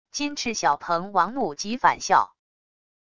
金翅小鹏王怒极反笑wav音频